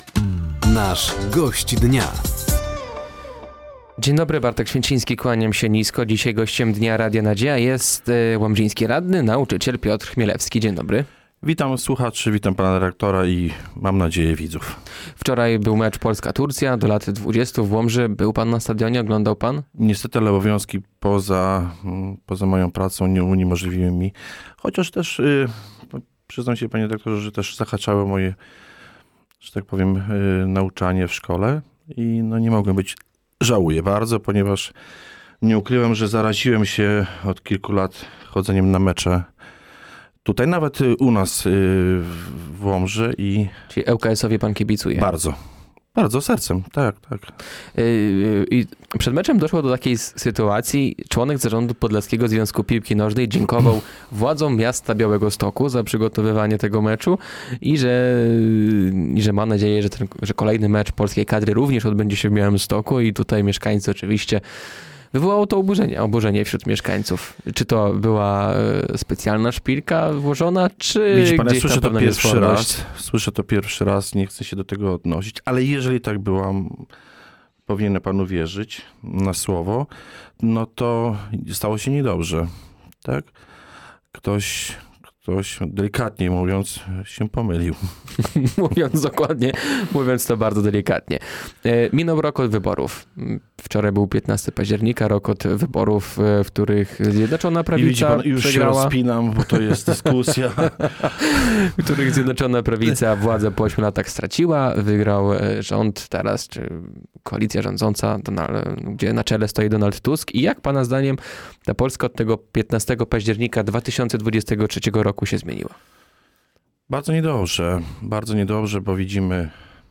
Gościem Dnia Radia Nadzieja był Piotr Chmielewski, nauczyciel i łomżyński radny Prawa i Sprawiedliwości. Tematem rozmowy była między innymi podwyżka podatków, mediateka oraz krajobraz kraju po roku od wyborów parlamentarnych.